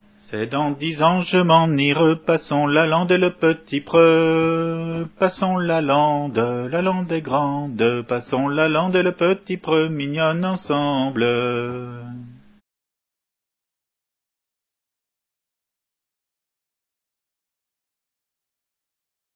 Entendu lors du stage de chant